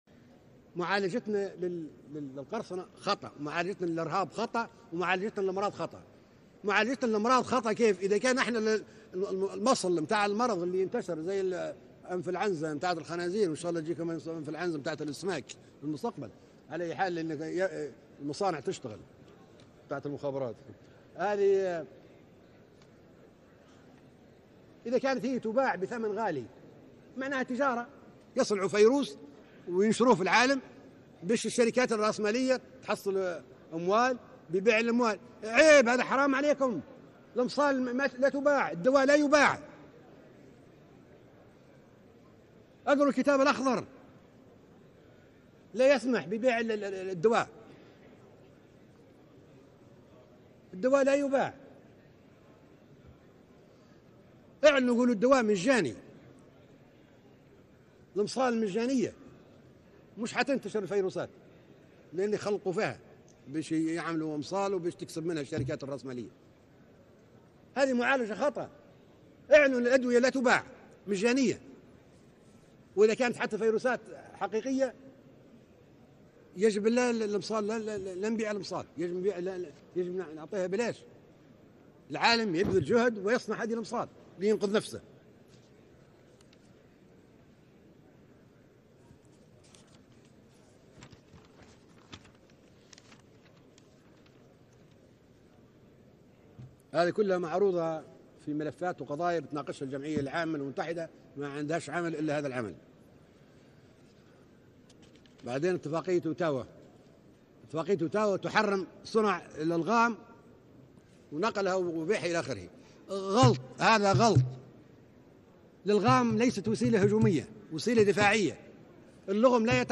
נאום קדאפי באום 2009 - וירוסים, חיסונים, יהודים וערבים - כתוביות בעברית
קטע קצר מנאומו הארוך בן ה שעה ו35 דקות של מועמר קדאפי, נשיא לוב לשעבר, שנשא במועצת האום ב 2009. בקטע זה קדאפי נוגע בנושא וירוסים, חיסונים ותרופות.